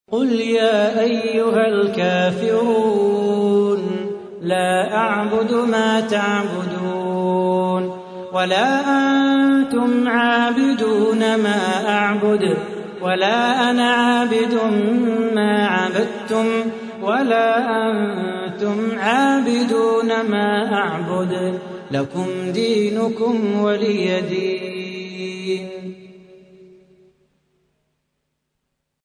تحميل : 109. سورة الكافرون / القارئ صلاح بو خاطر / القرآن الكريم / موقع يا حسين